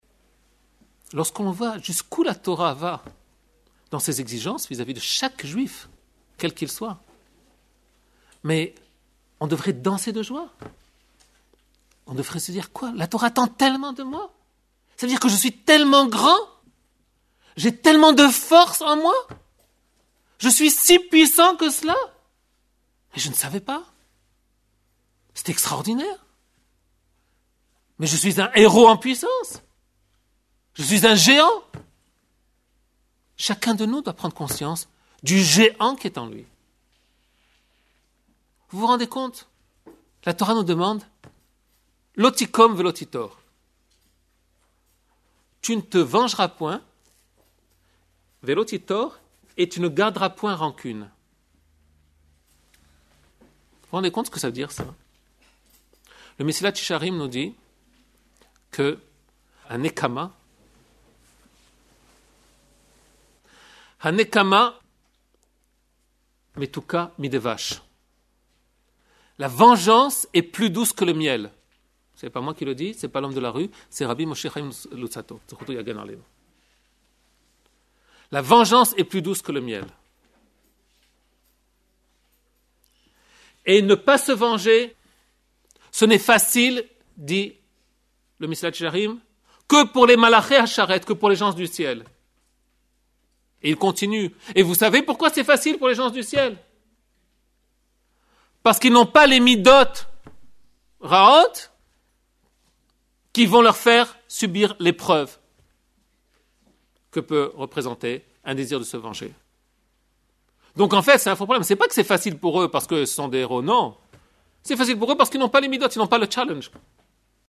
Il a été donné à Sarcelles le 19 Elloul 5757 – 21 Septembre 1997, Parashath Nitzaviv-Vayélekh.